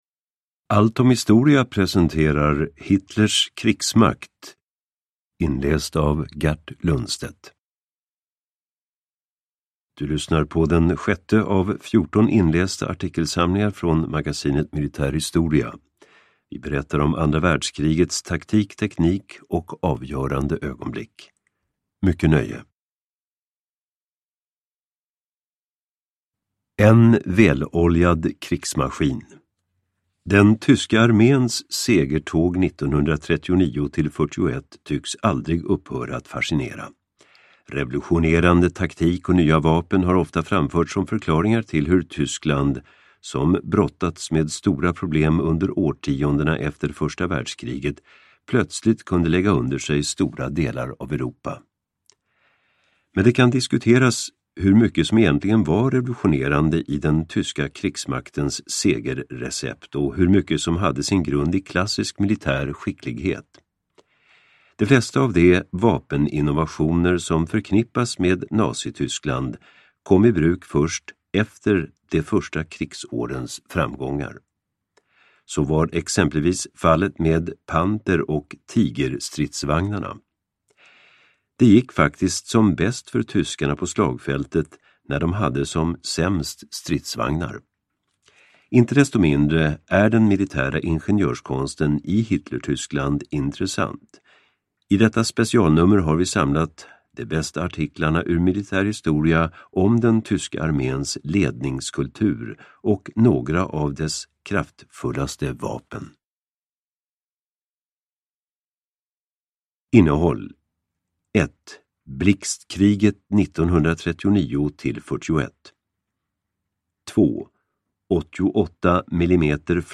Hitlers krigsmakt (ljudbok) av Allt om Historia